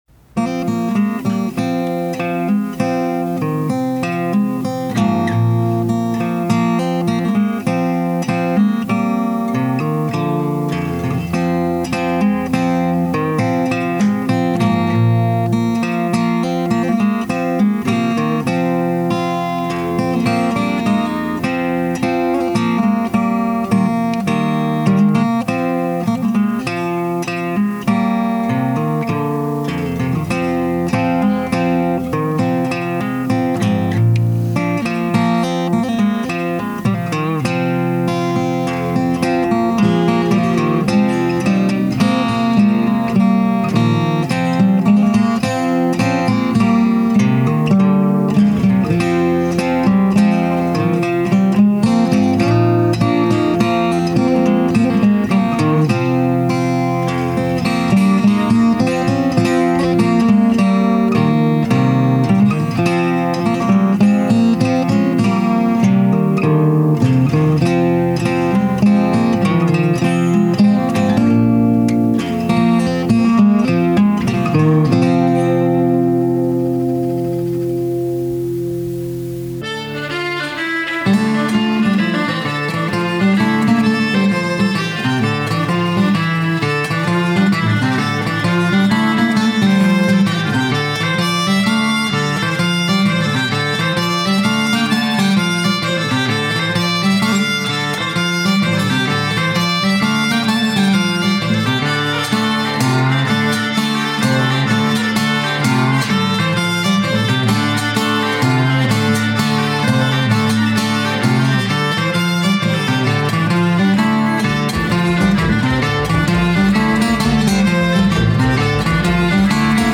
A remastered version of an instrumental recorded in an 8-track studio in the 80s, originally as the playout for a song called ‘The Weekends’, which was put to the same ‘Dives & Lazarus’ tune.
Unfortunately, the mastertapes from those sessions didn’t survive very well.
In particular, the fiddle is harsh and the bodhrán muffled, but it is what it is.
Guitars by me, fiddle
bodhrán
remastered_air-and-slip-jig-1.mp3